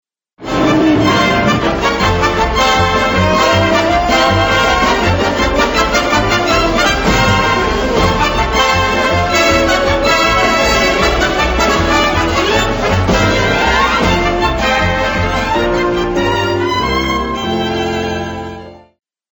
Funny